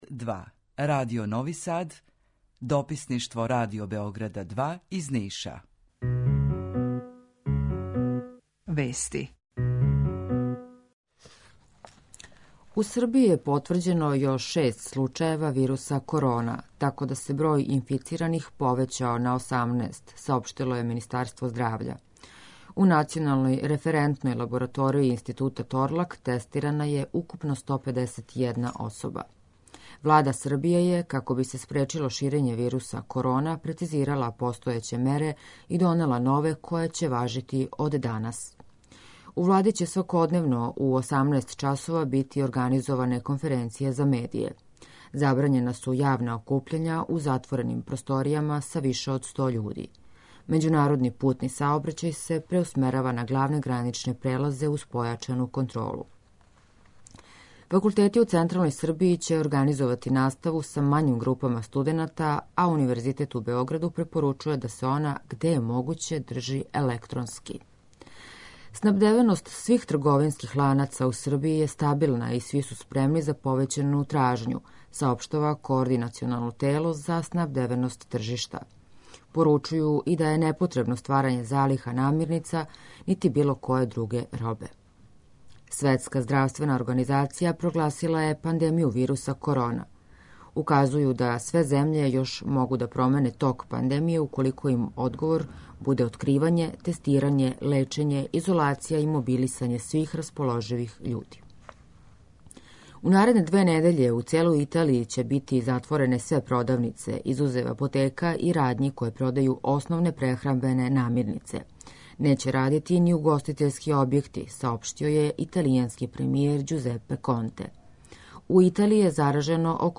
Јутарњи програм из три студија